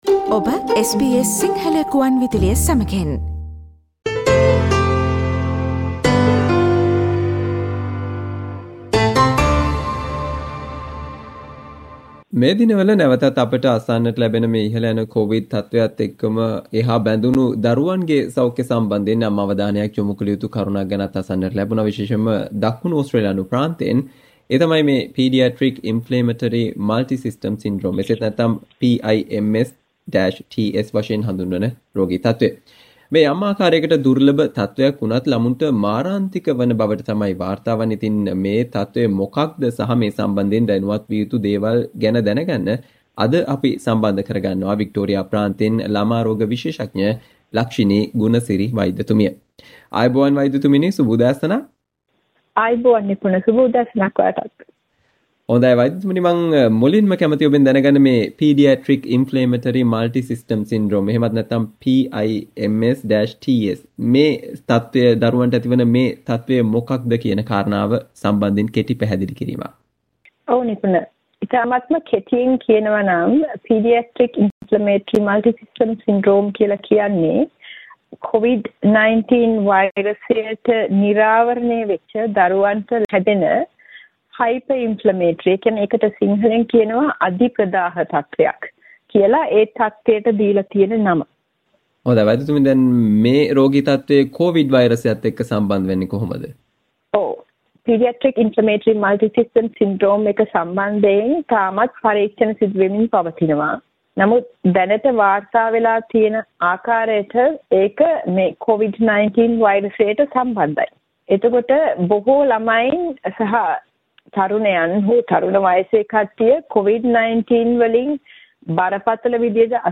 කොවිඩ් තත්වය අතරතුරේ ඕස්ට්‍රේලියාවෙන් වාර්තා වන ළමුන්ට මාරාන්තික විය හැකි Paediatric multisystem inflammatory syndrome තත්වය සම්බන්ධයෙන් වන සාකච්චාවට සවන්දෙන්න